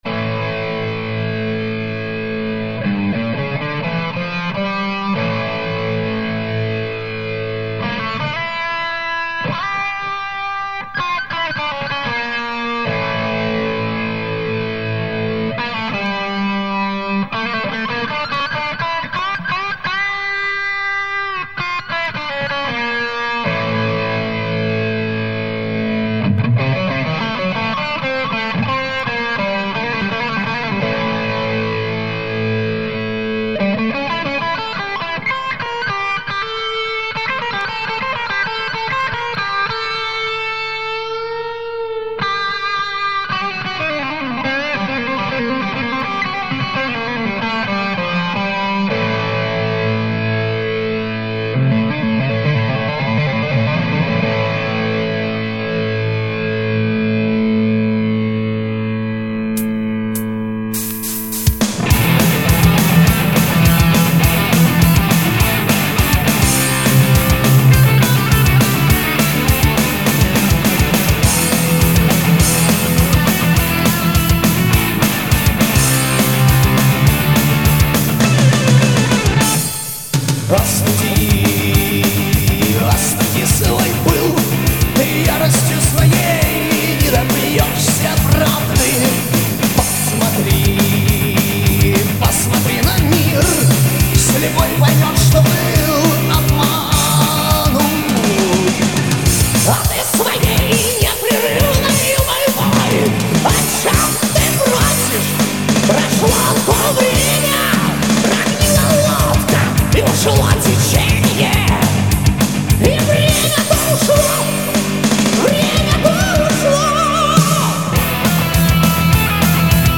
Играет в стилях: Hard Rock , Heavy Metal